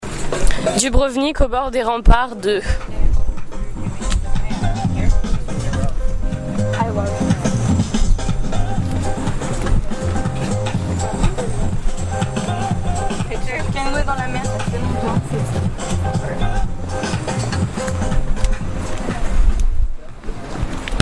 près du café Buza (un bar magnifique avec une vue sur la mer) en direction du bord de mer.